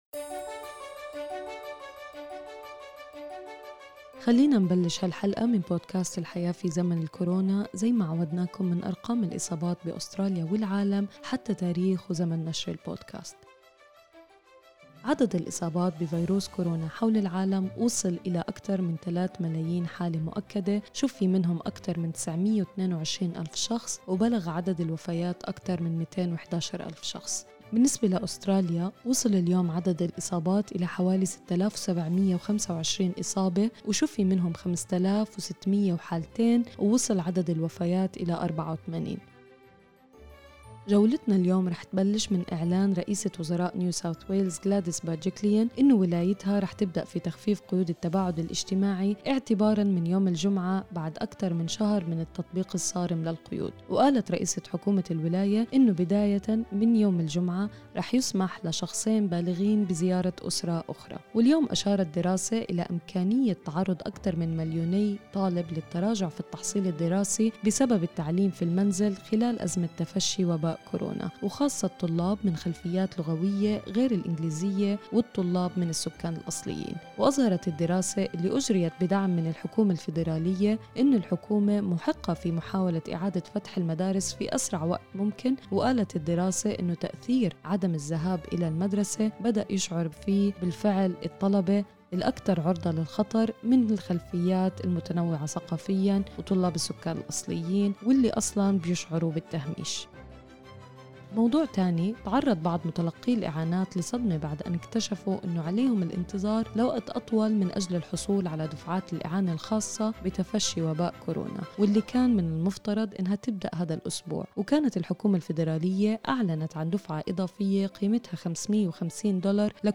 أخبار الكورونا اليوم 28/4/2020